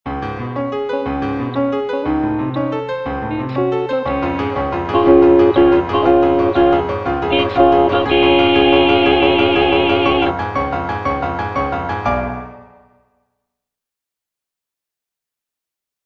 G. BIZET - CHOIRS FROM "CARMEN" En route, en route (tenor II) (Emphasised voice and other voices) Ads stop: auto-stop